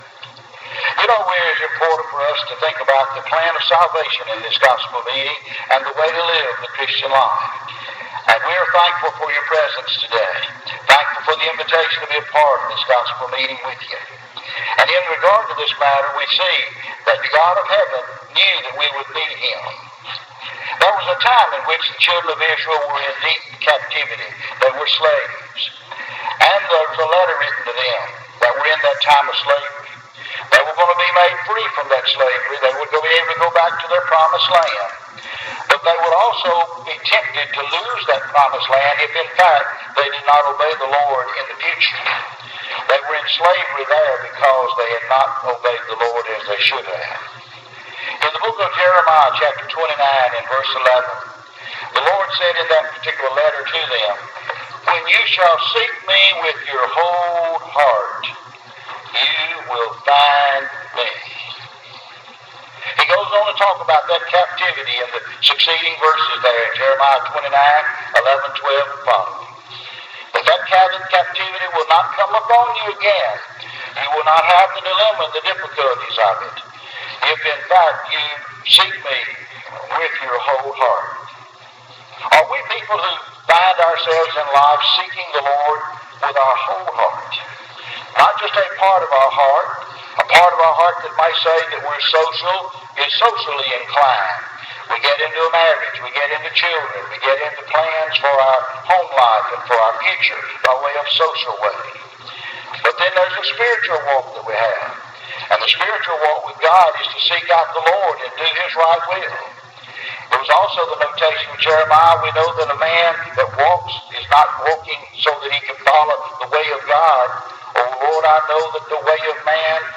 2015-Summer-Sermons.mp3